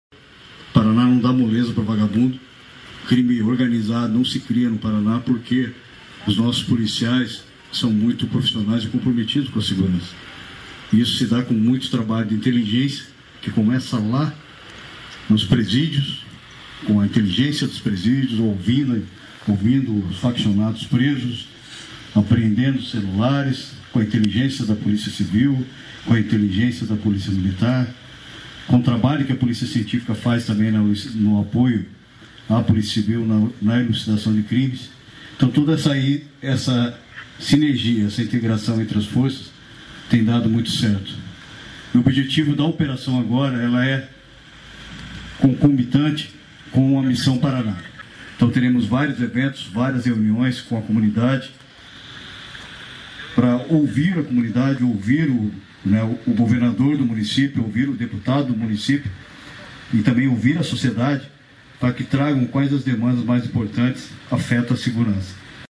Sonora do secretário Estadual de Segurança Pública, Hudson Teixeira, sobre a Missão Paraná II